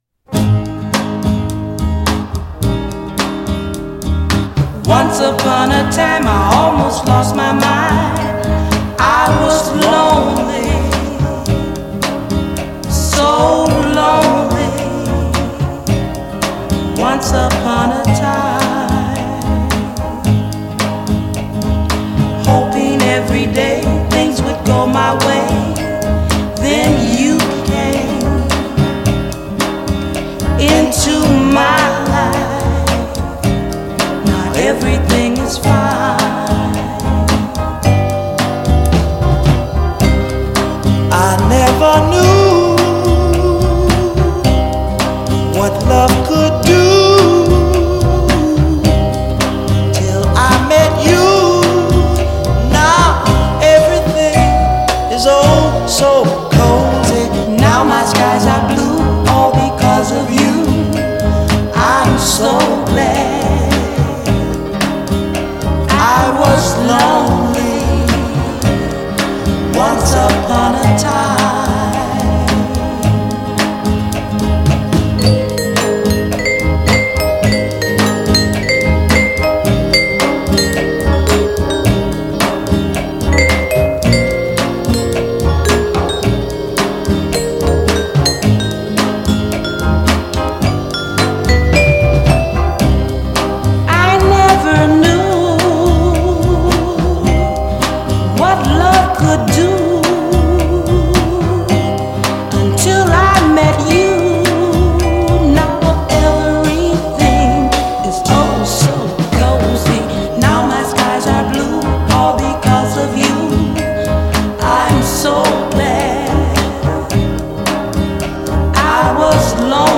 Single Version _ Mono